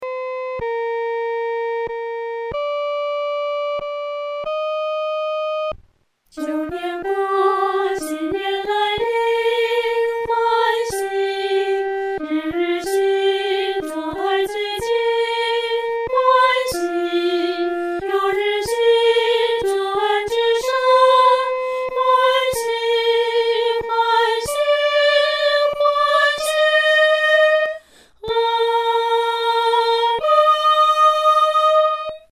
女高
本首圣诗由网上圣诗班录制